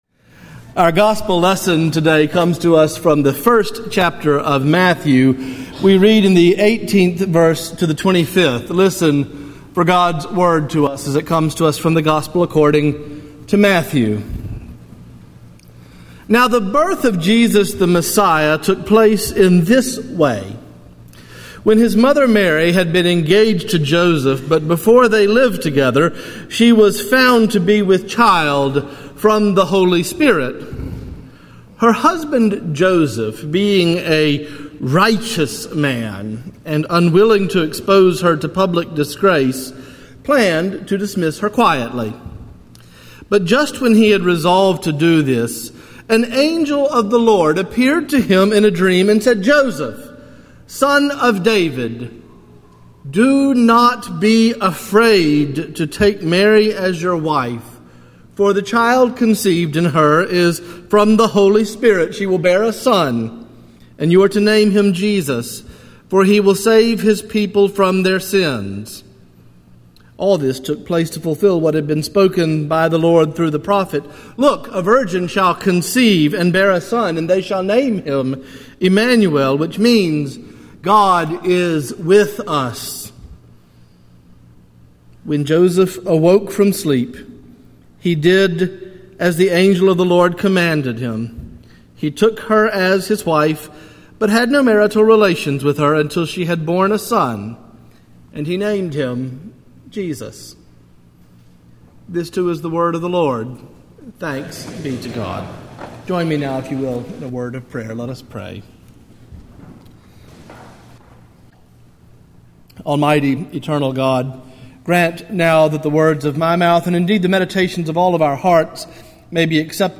Morningside Presbyterian Church - Atlanta, GA: Sermons: A Righteous Man